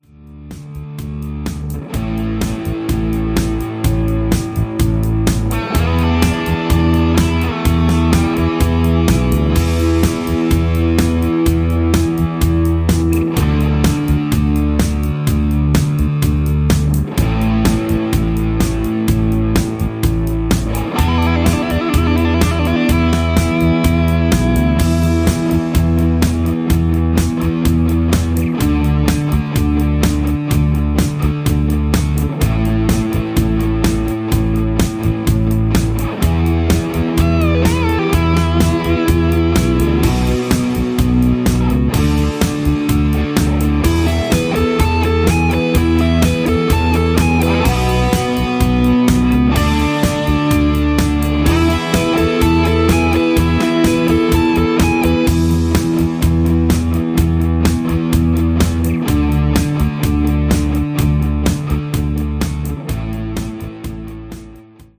Instrumental with No Leads
Singing Calls